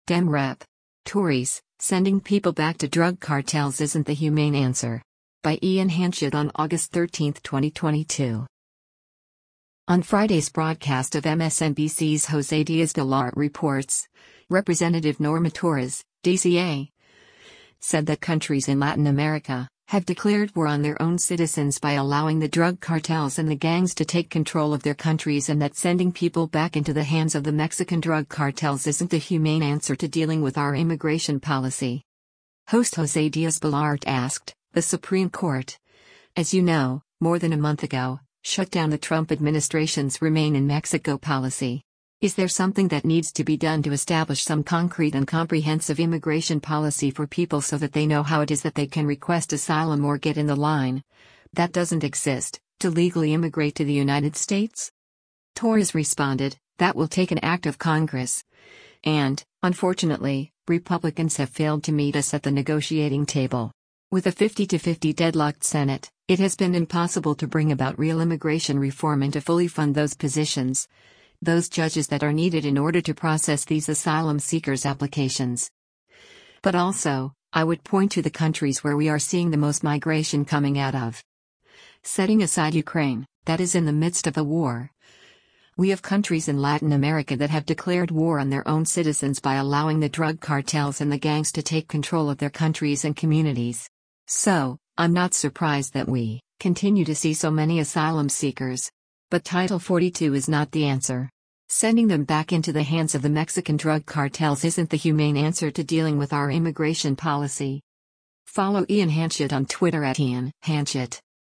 On Friday’s broadcast of MSNBC’s “Jose Diaz-Balart Reports,” Rep. Norma Torres (D-CA) said that “countries in Latin America…have declared war on their own citizens by allowing the drug cartels and the gangs to take control of their countries” and that sending people “back into the hands of the Mexican drug cartels isn’t the humane answer to dealing with our immigration policy.”